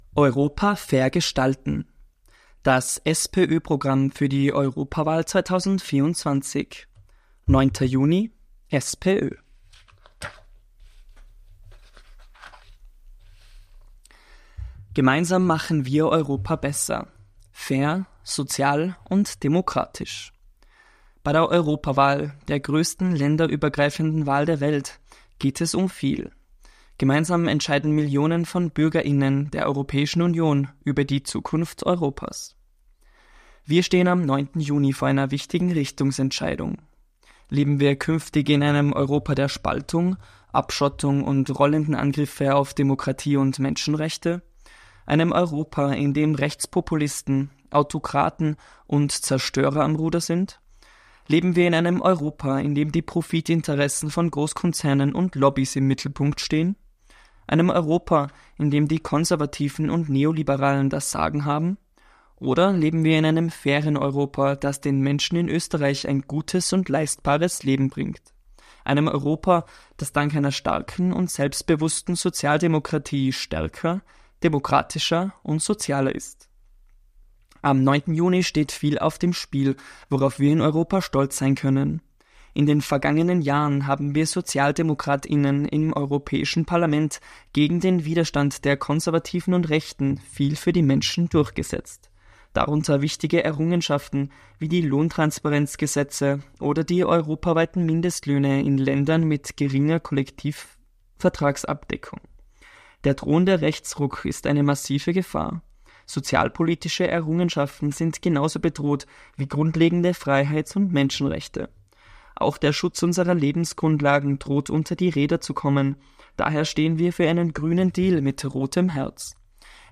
Das ganze EU-Wahlprogramm der SPÖ am Stück gelesen | Europawahl 2024